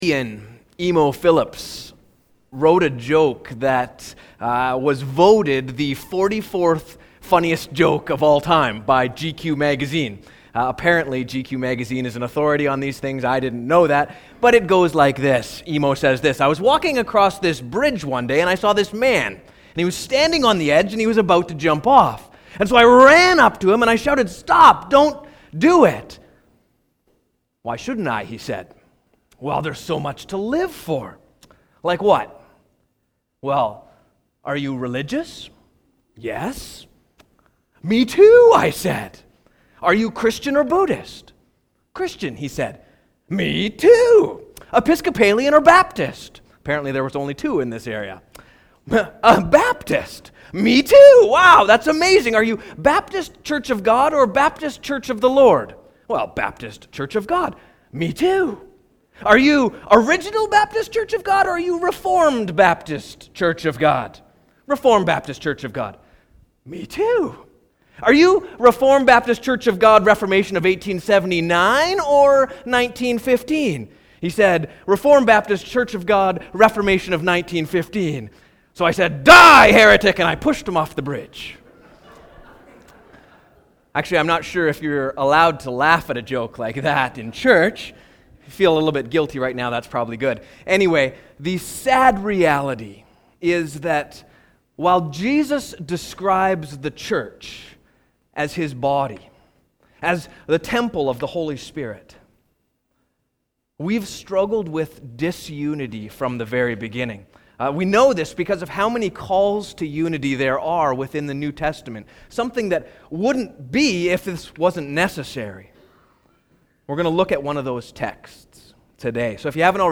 Sermons | Fort George Baptist Church